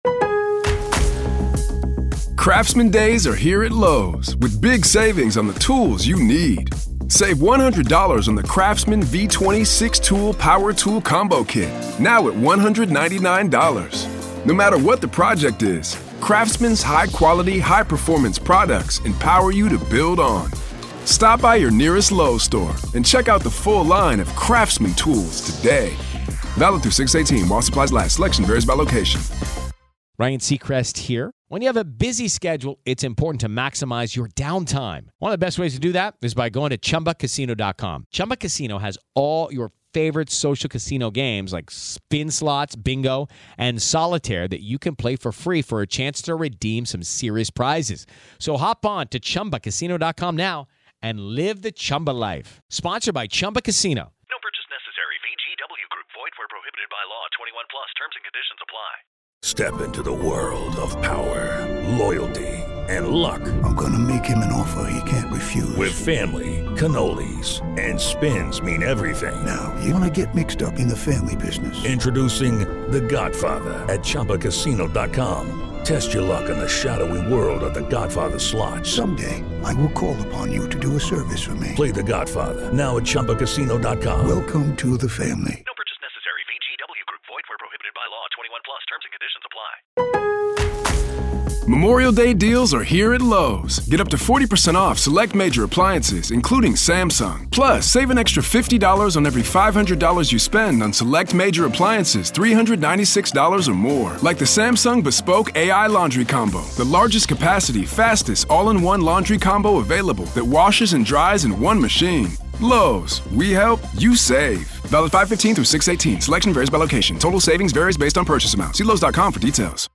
This significant interview took place at the family's Moselle property in South Carolina, a location central to the ongoing investigation. The interview, which occurred in June 2021, was not just a procedural step but a vital component in understanding the broader context and family dynamics surrounding the incident.